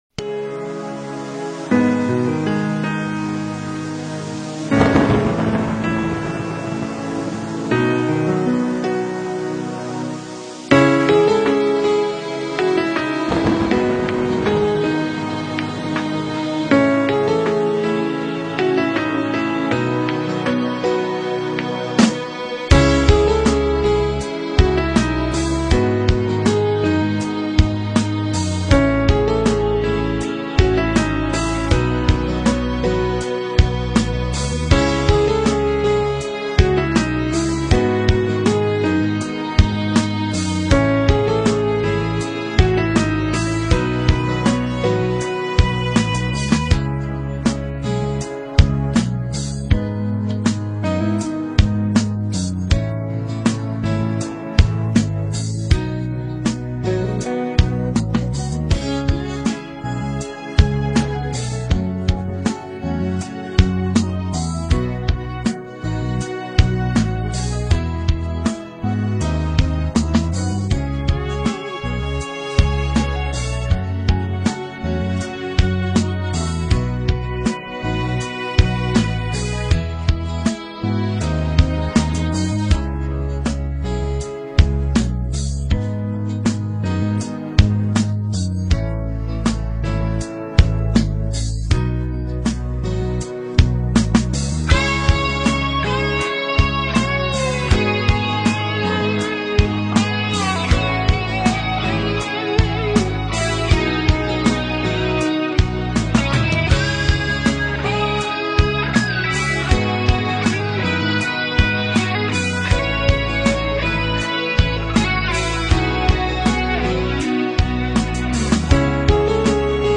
বাংলা কারাওকে।